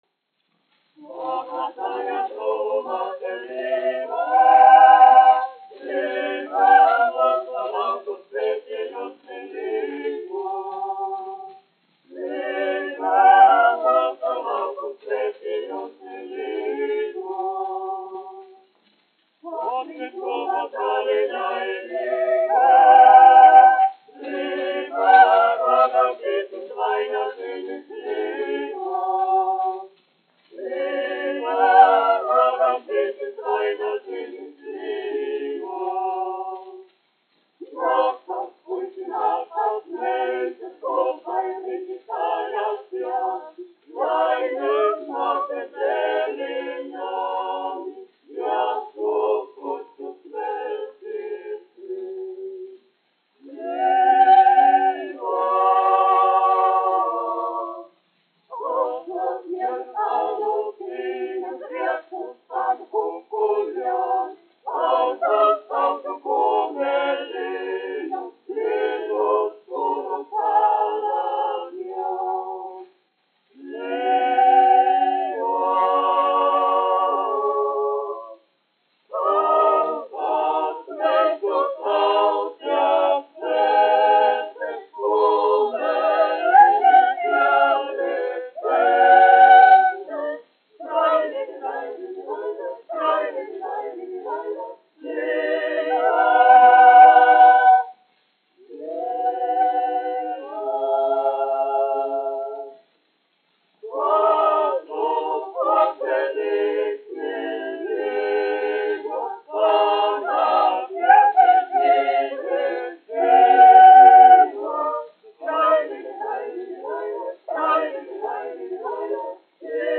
Rīgas Latviešu dziedāšanas biedrības jauktais koris, izpildītājs
1 skpl. : analogs, 78 apgr/min, mono ; 25 cm
Latviešu tautasdziesmas
Kori (jauktie)
Skaņuplate